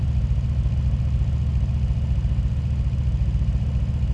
v8_10_idle.wav